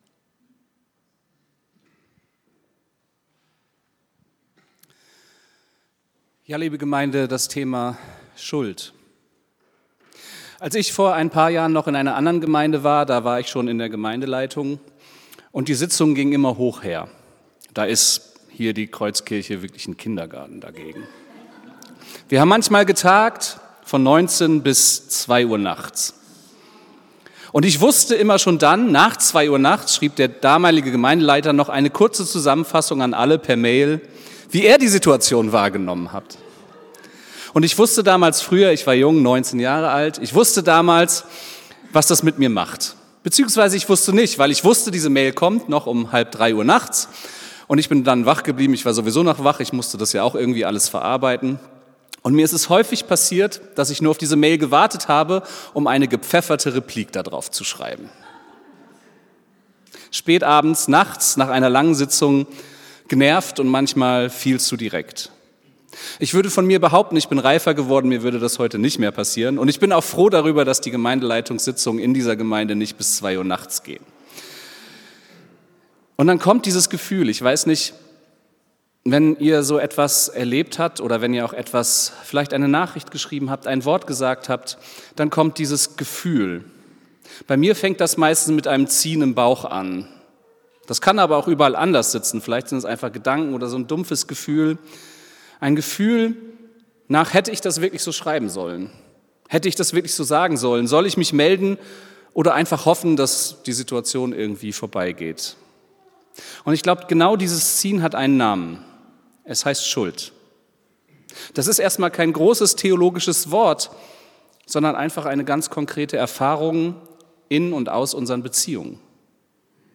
Predigt vom 22.02.2026